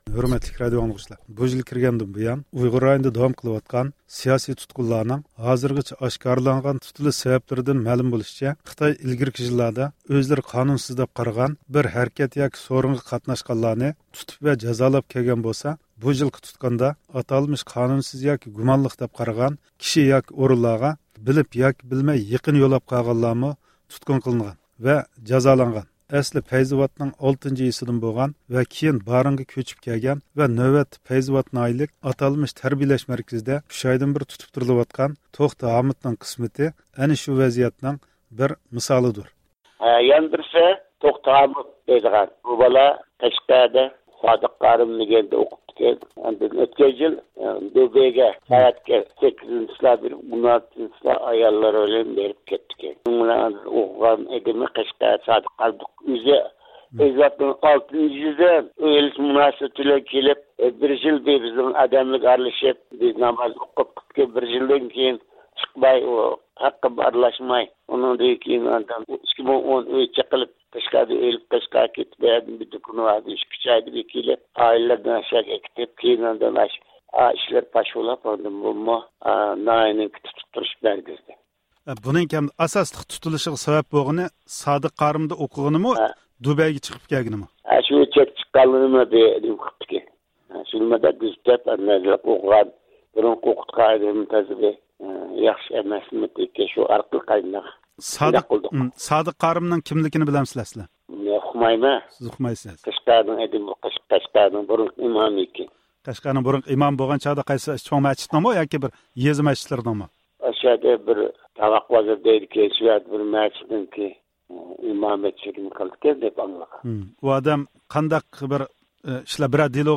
ئەركىن ئاسىيا رادىئوسى مۇخبىرىنىڭ ئېلىپ بارغان تېلىفۇن زىيارىتى تەپسىلات يۇقىرى ئاۋاز ئۇلىنىشى ئارقىلىق بېرىلدى.